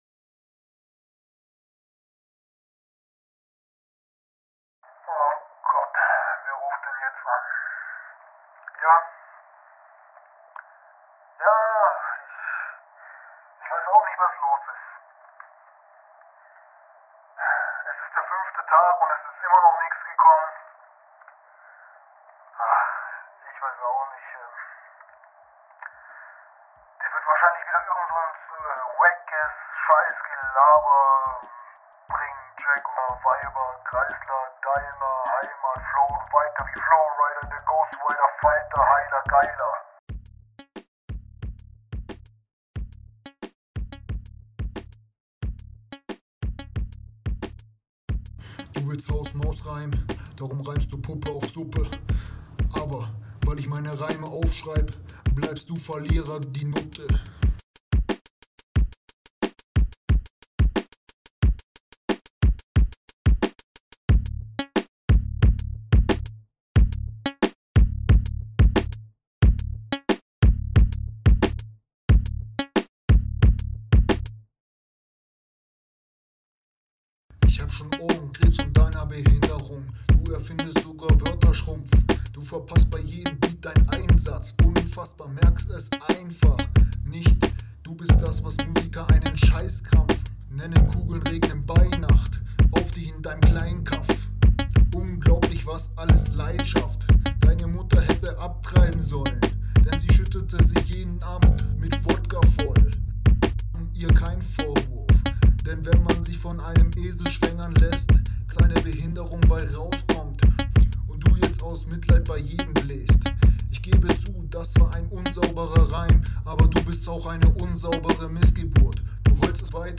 Hier ist leider wieder 5 Sekunden Stille und mit dem Echo (denke sollte ein Handy …